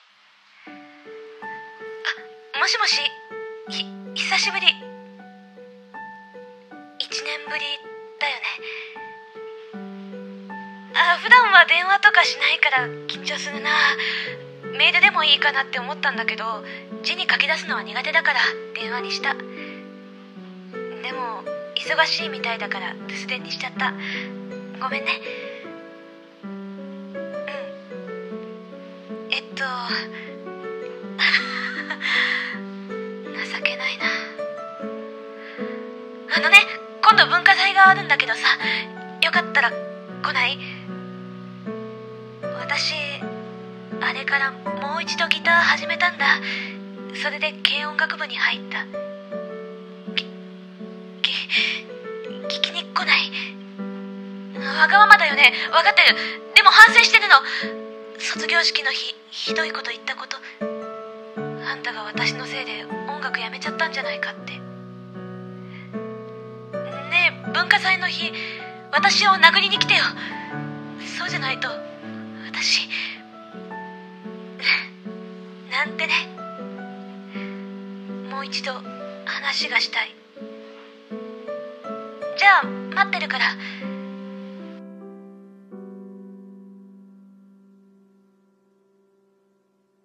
声劇】 話がしたいんだ。